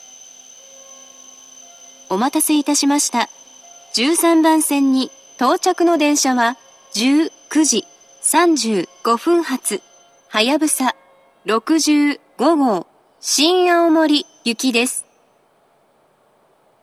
１３番線到着放送